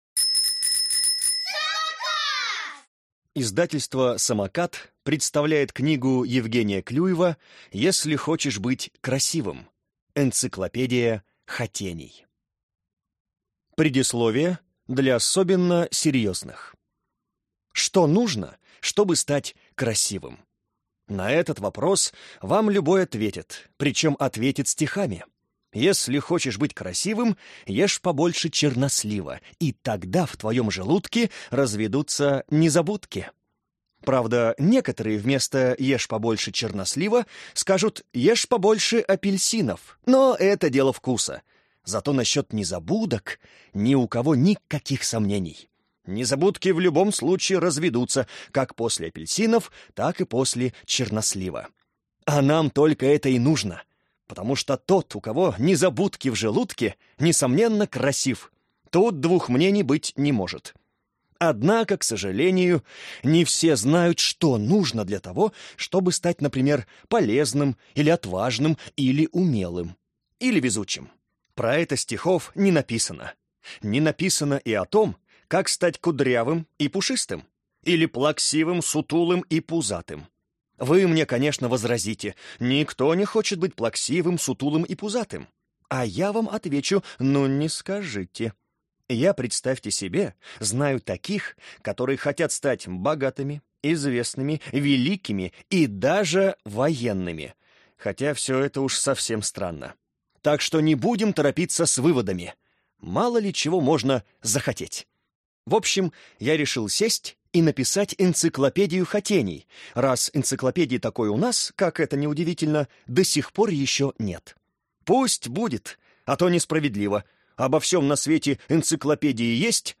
Аудиокнига Если хочешь быть красивым | Библиотека аудиокниг